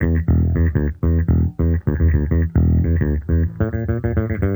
Index of /musicradar/sampled-funk-soul-samples/105bpm/Bass
SSF_JBassProc2_105E.wav